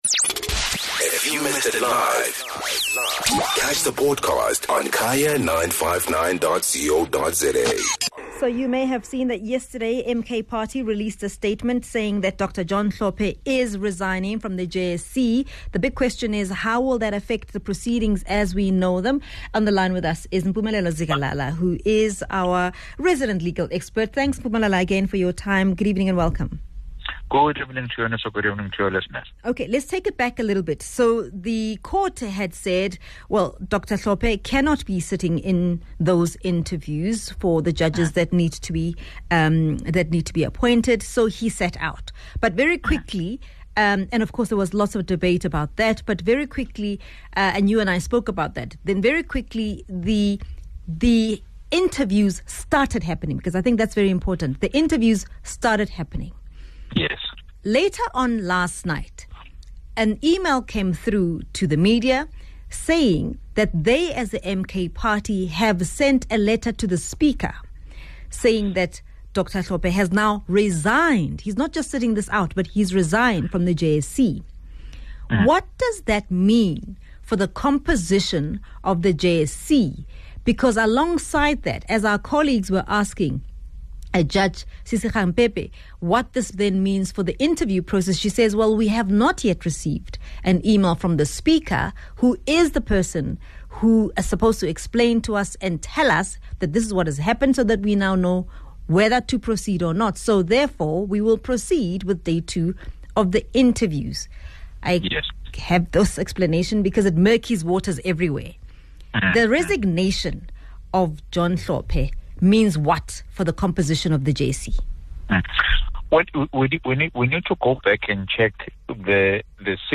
legal expert